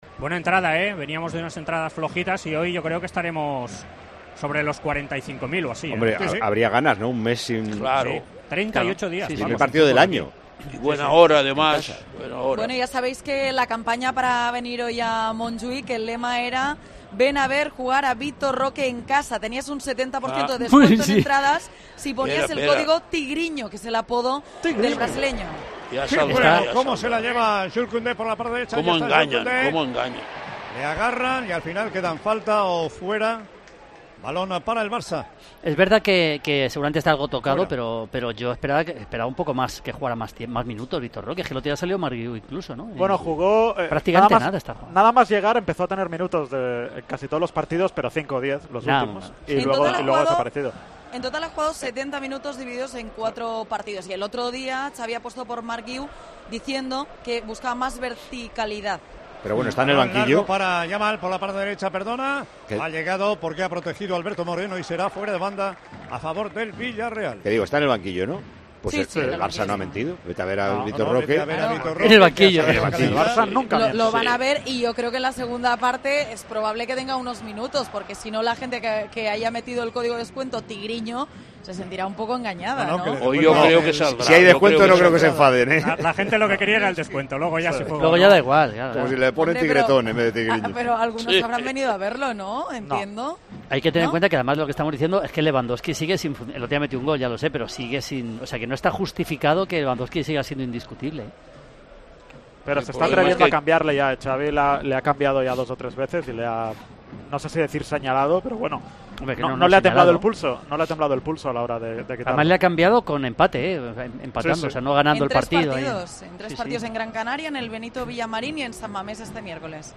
Con el objetivo de atraer a gente a este partido, el equipo culé lanzó una promoción con hasta un 70% de descuento para socios. En Tiempo de Juego, durante la retransmisión del encuentro, hemos tratado este tema con nuestro equipo de comentaristas.